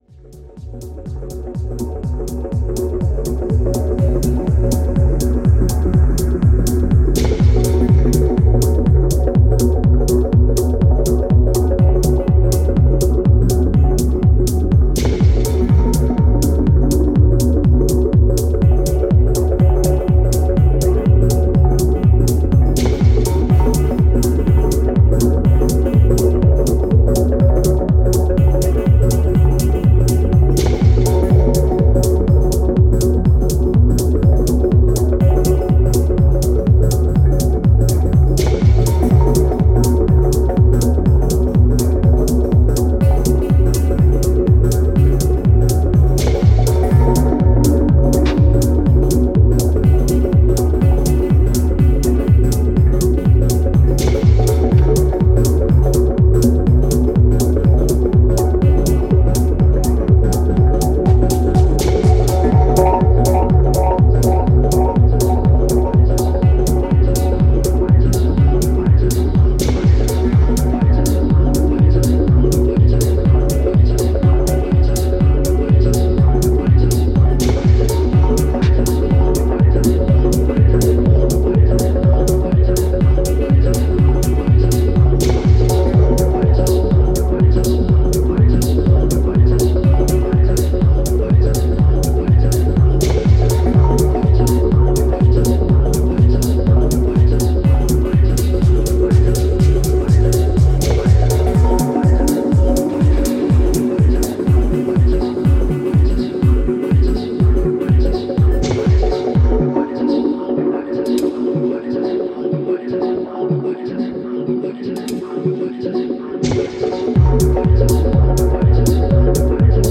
filigree and atmospheric journey